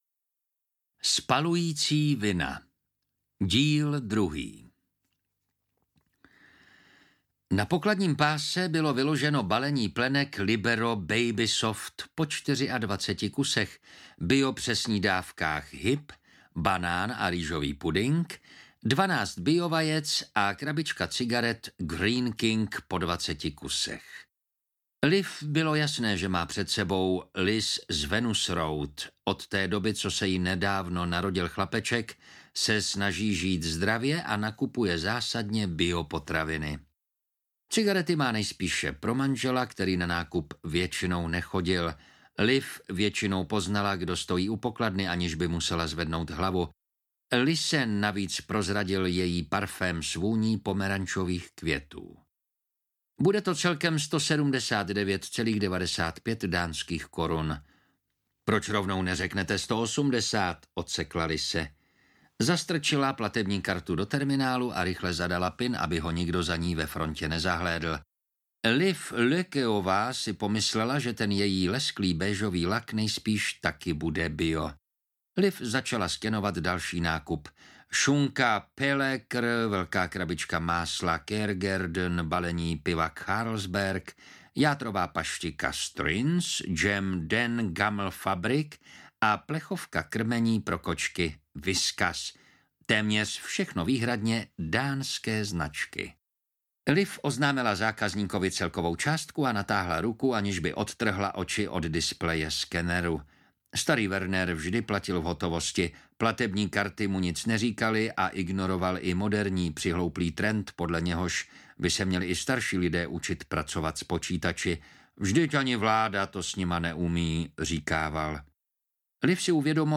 Spalující vina - Díl 2 audiokniha
Ukázka z knihy